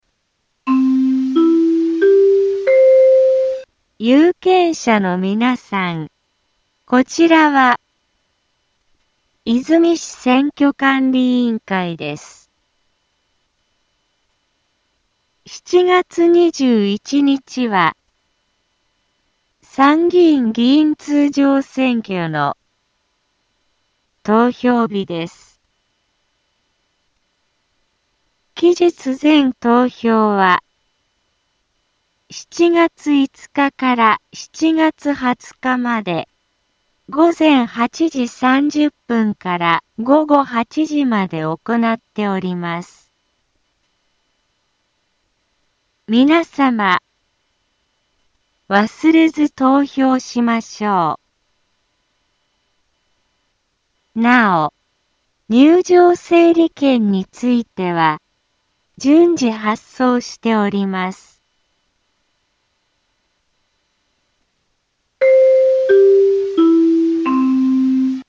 Back Home 災害情報 音声放送 再生 災害情報 カテゴリ：通常放送 住所：大阪府和泉市府中町２丁目７−５ インフォメーション：有権者のみなさん こちらは、和泉市選挙管理委員会です。 7月21日は、参議院議員通常選挙の、投票日です。